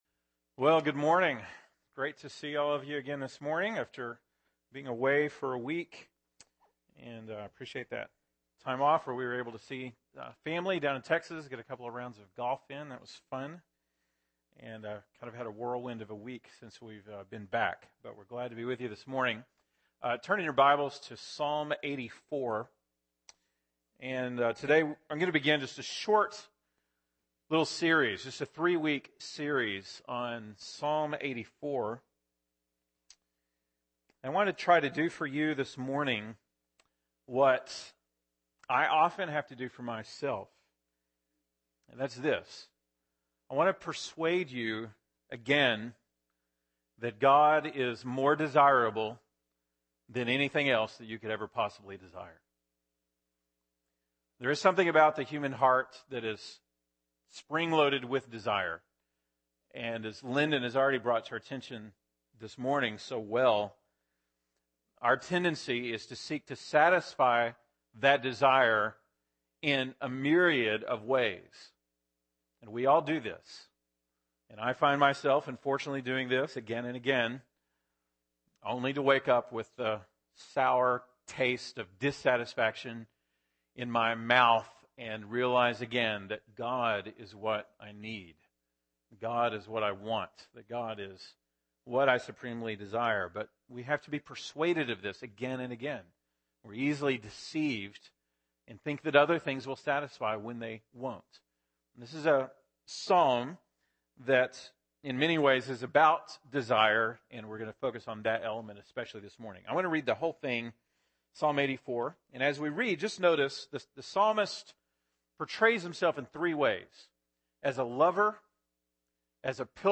April 19, 2015 (Sunday Morning)